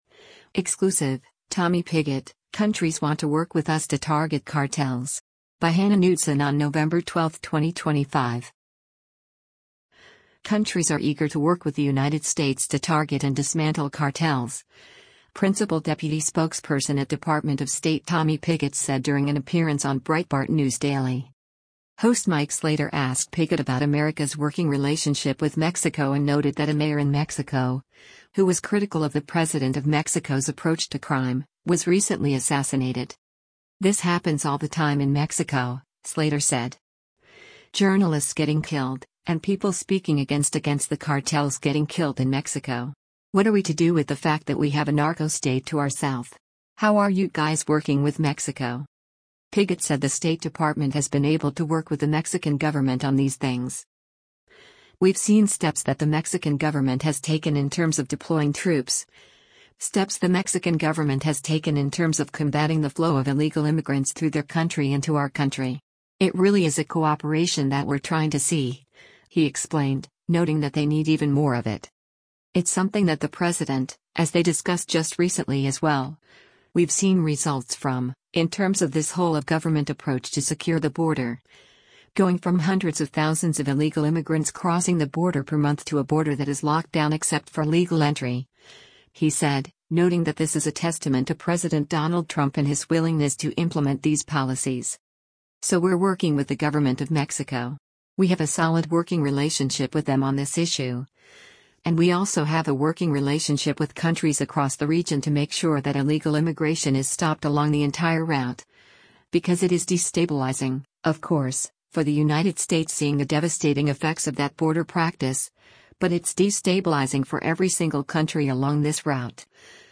Countries are eager to work with the United States to target and dismantle cartels, Principal Deputy Spokesperson at Department of State Tommy Pigott said during an appearance on Breitbart News Daily.
Breitbart News Daily airs on SiriusXM Patriot 125 from 6:00 a.m. to 9:00 a.m. Eastern.